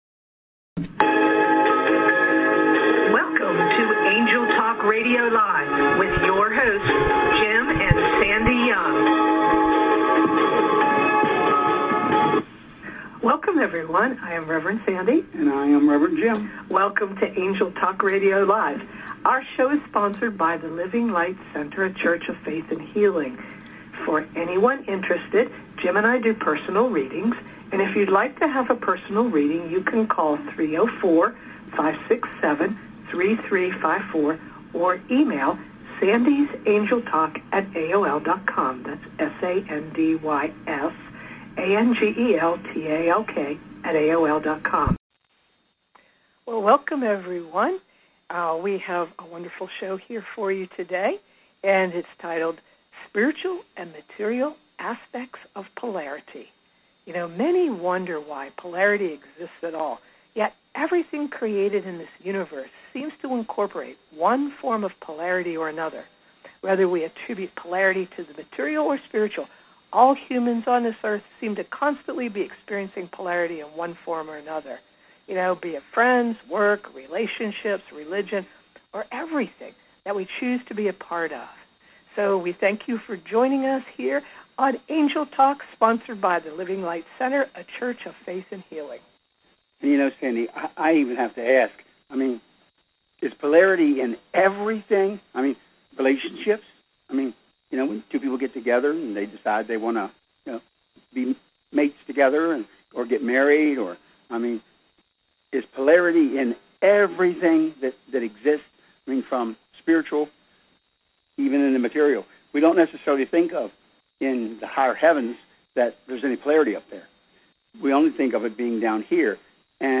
Join us for another live service on Angel Talk Radio Show.